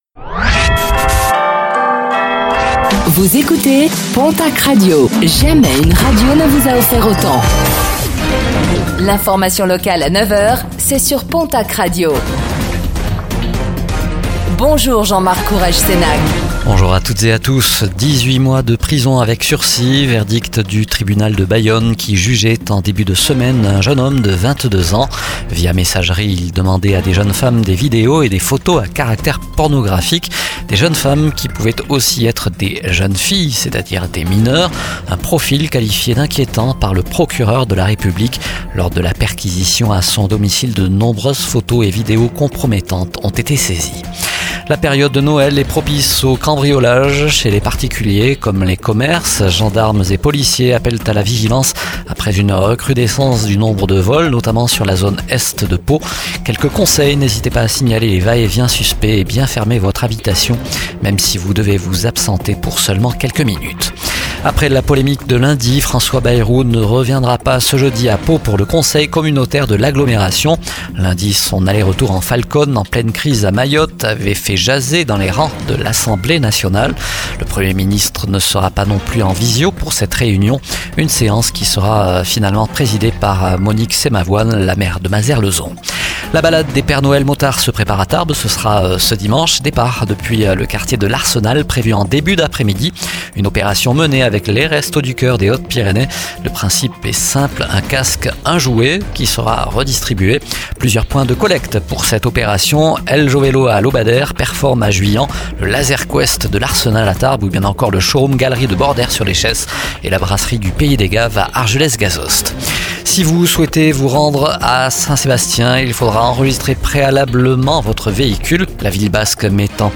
09:05 Écouter le podcast Télécharger le podcast Réécoutez le flash d'information locale de ce jeudi 19 décembre 2024